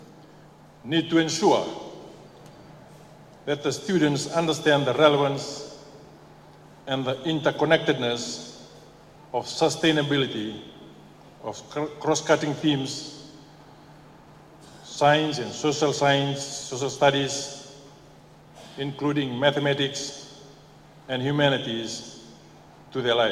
Speaking at the Fiji Head Teachers Annual Conference in Suva yesterday, Radrodro emphasized the need to bolster students’ critical thinking and problem-solving abilities.